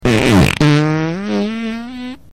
放屁
fangpi.mp3